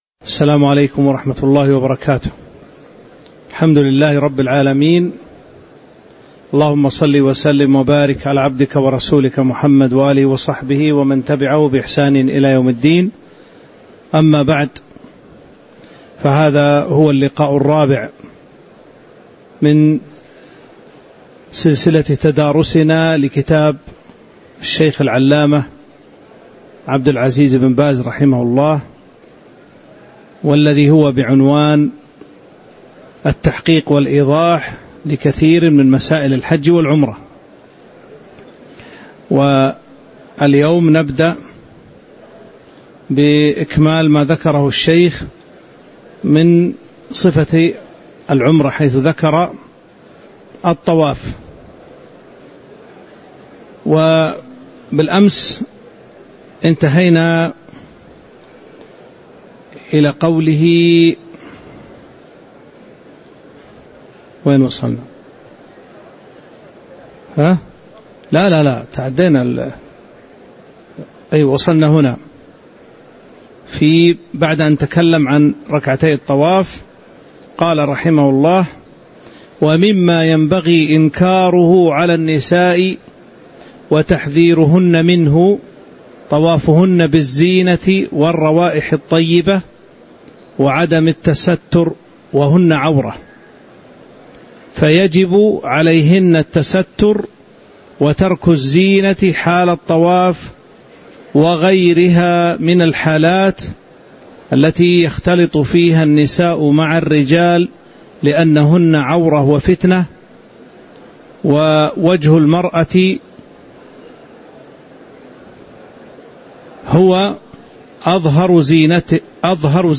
تاريخ النشر ٢٣ ذو القعدة ١٤٤٦ هـ المكان: المسجد النبوي الشيخ